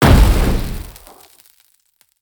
spell-impact-5.mp3